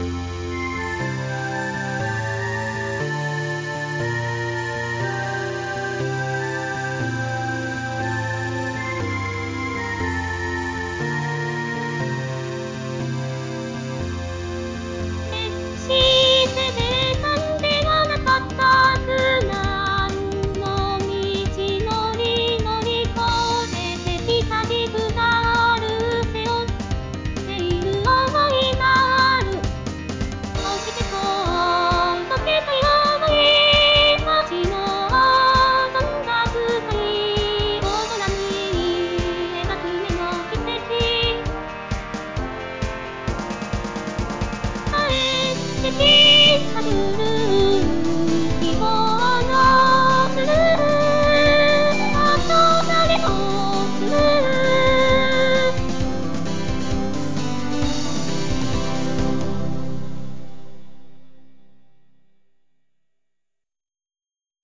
日本語歌詞から作曲し、伴奏つき合成音声で歌います。